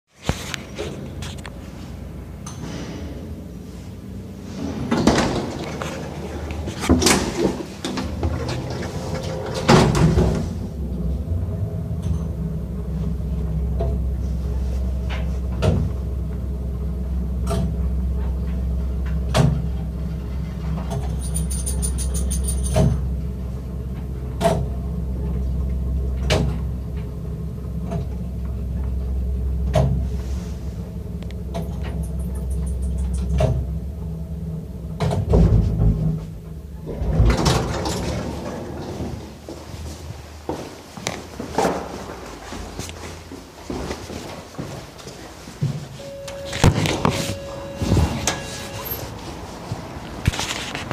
Звуки лифта
Звук поездки на лифте в Москве записанный на диктофон